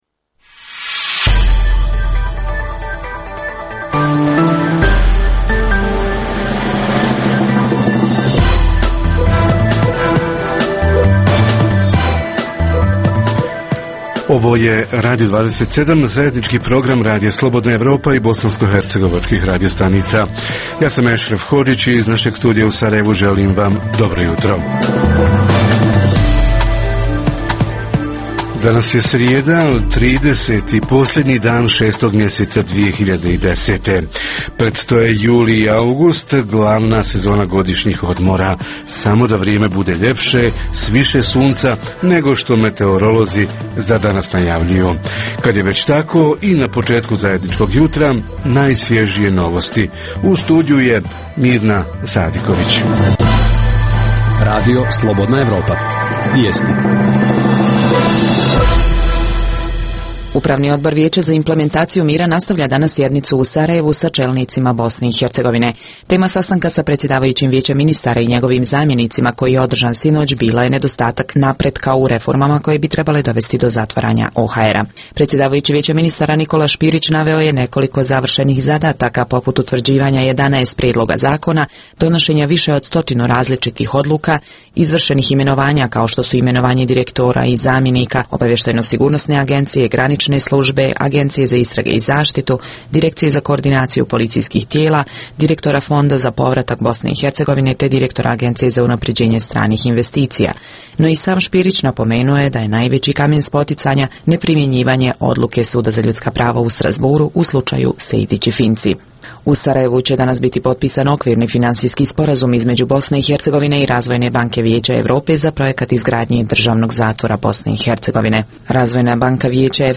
Učenici generacije – hoće li biti u prilici da uz svoju radinost i talenat postižu još veće uspjehe? Reporteri iz cijele BiH javljaju o najaktuelnijim događajima u njihovim sredinama.